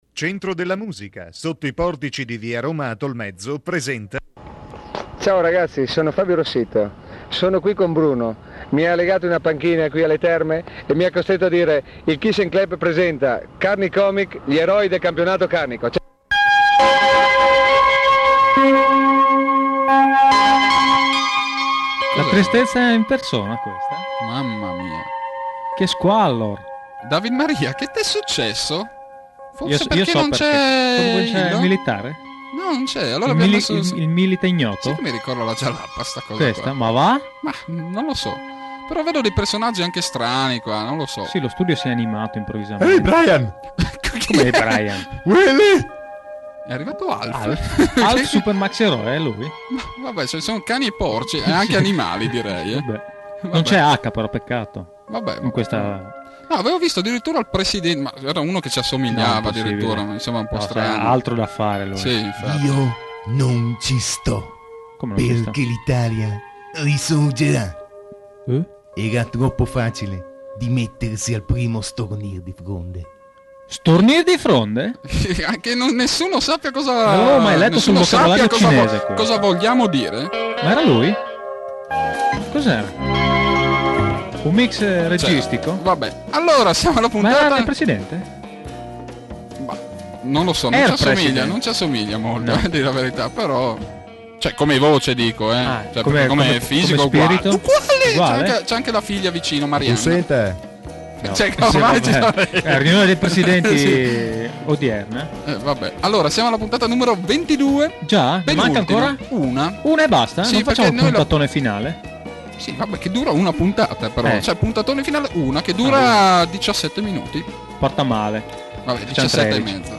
Proponiamo il podcast della penultima puntata dell’edizione ’95 del Kissene Kleb, la trasmissione satirica in onda negli anni ’90 su Radio Studio Nord e dedicata, nella versione pomeridiana, agli “strafalcioni” dei giornalisti che seguivano il Campionato Carnico.